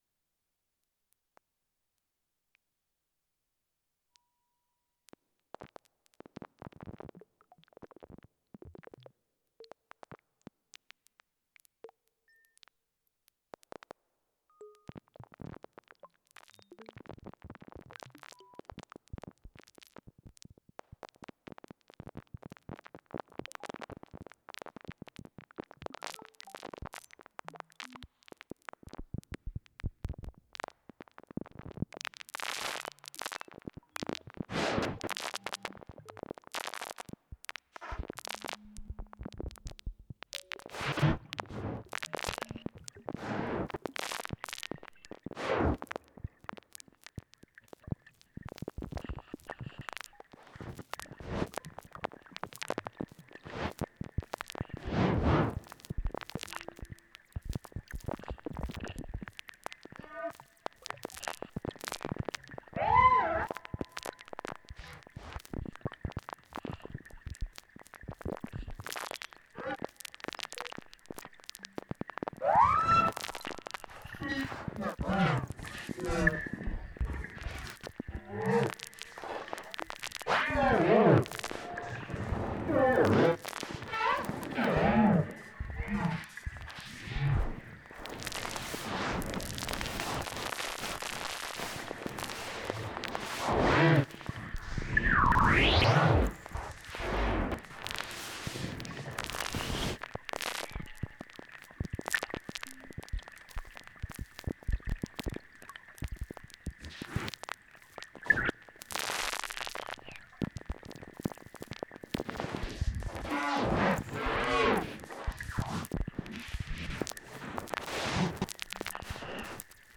A sea-image-sound performance composed and recorded in Mi’kma’ki / Nova Scotia, Fall 2020.